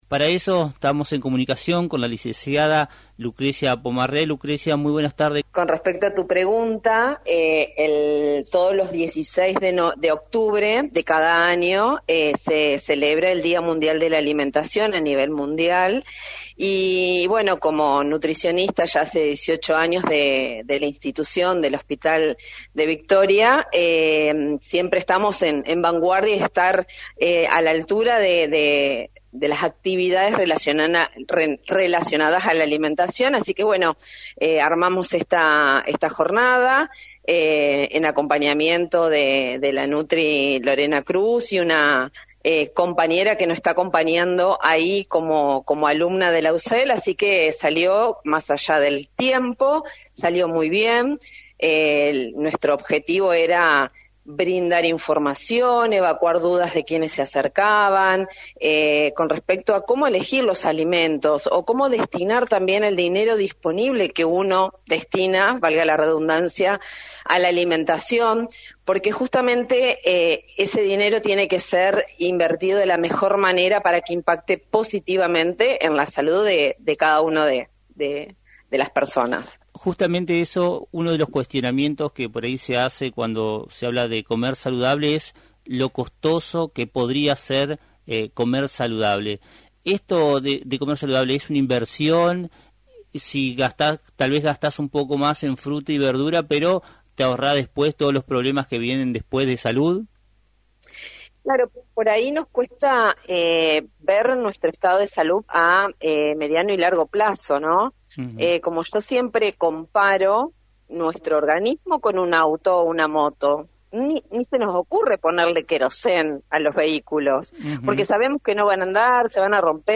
En diálogo con el programa “Puntos Comunes” de Lt39